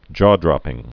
(jôdrŏpĭng)